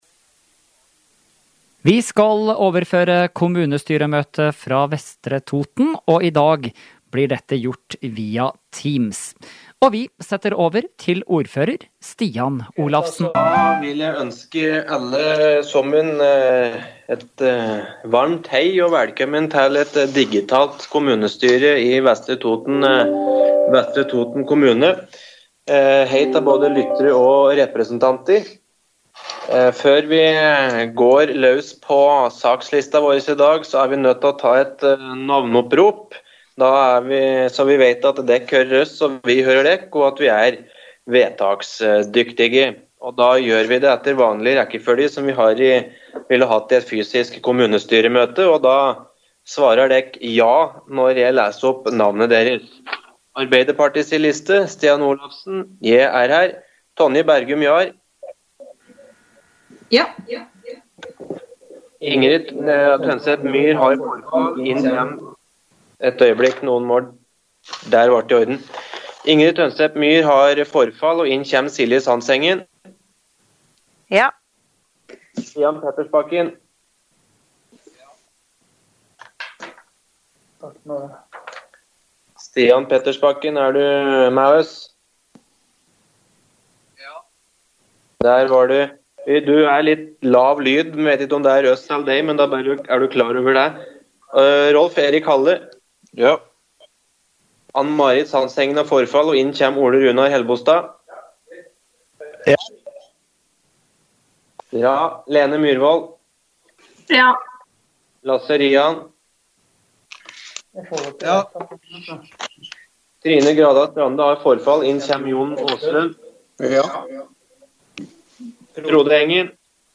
Kommunestyremøte fra Vestre Toten 26. november – Lydfiler lagt ut | Radio Toten